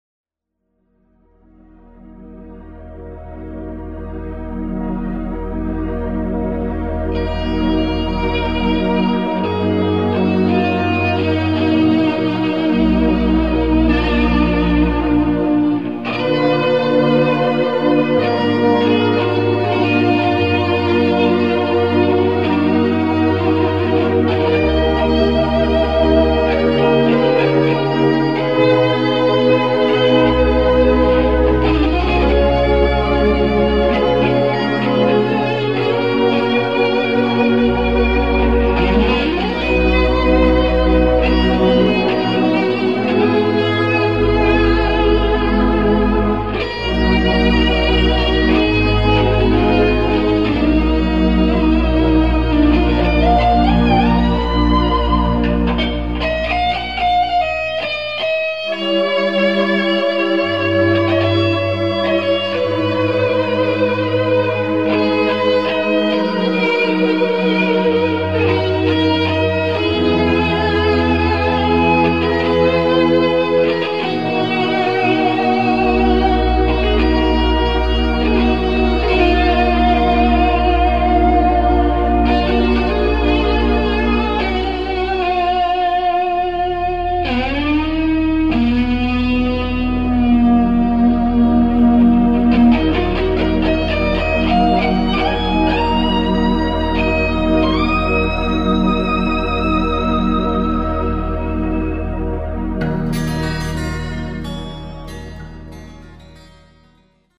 Köpte mig en elgitarr för en månad sedan.
Ibland låter det helt fördjävligt när det är midi-ljud men det är fan så...